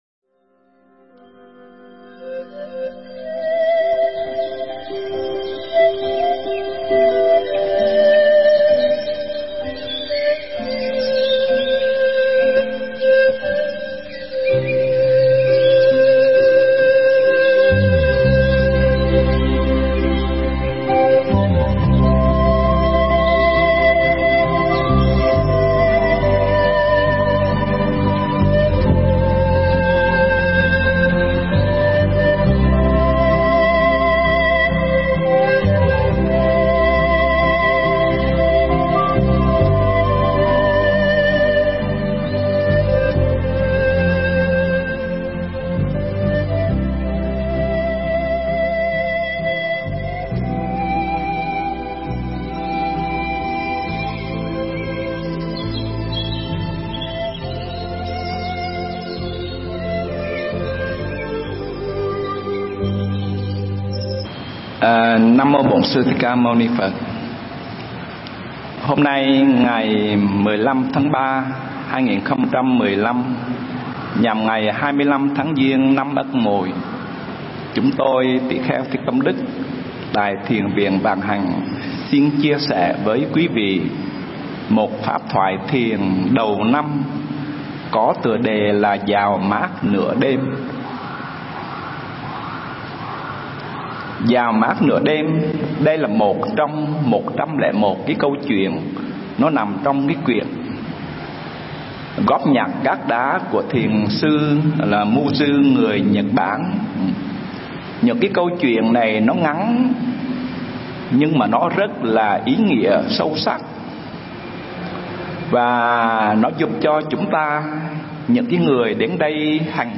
Nghe Mp3 thuyết pháp Dạo Mát Nửa Đêm